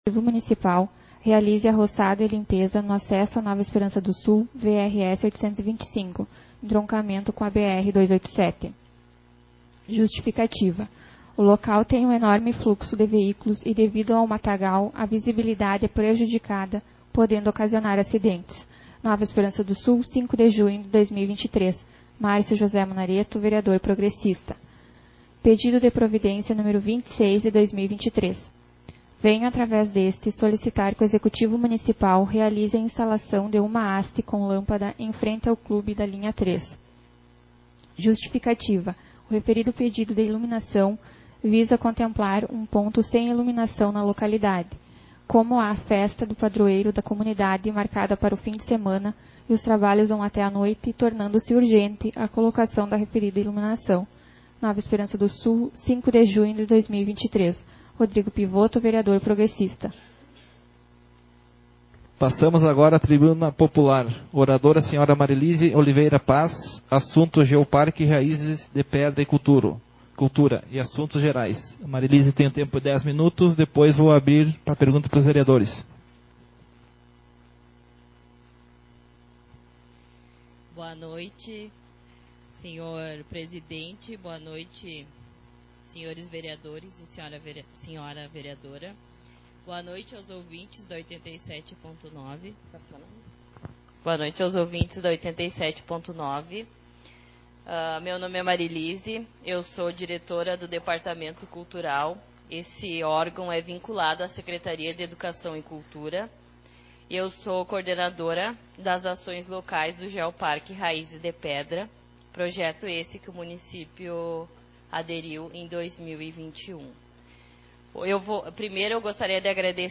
Sessão Ordinária 16/2023